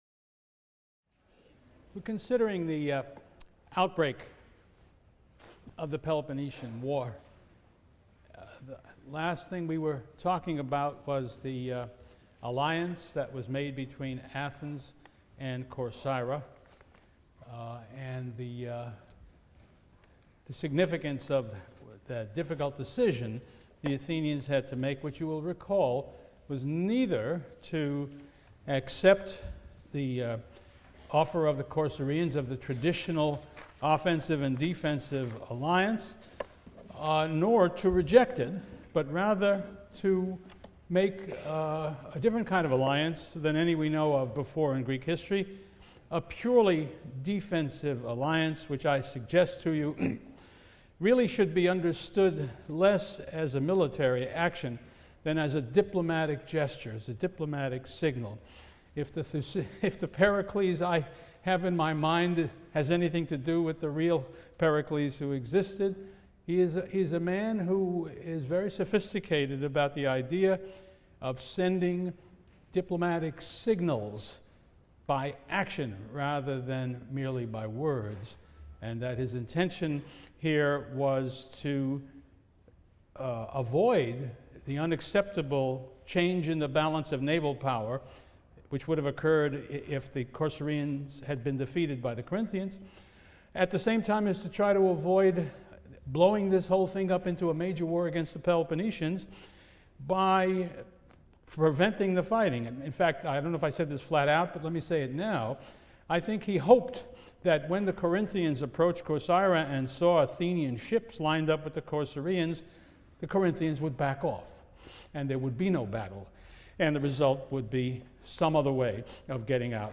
CLCV 205 - Lecture 19 - The Peloponnesian War, Part II | Open Yale Courses